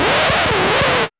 2805R SCRTCH.wav